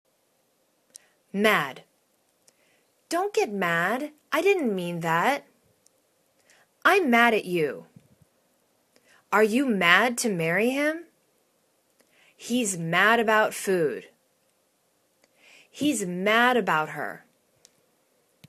mad     /mad/    adj